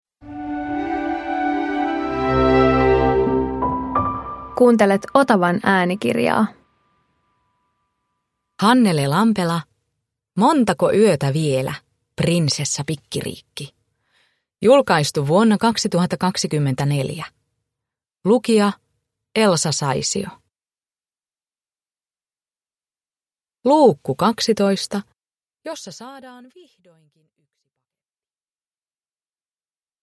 Montako yötä vielä, Prinsessa Pikkiriikki 12 – Ljudbok
Uppläsare: Elsa Saisio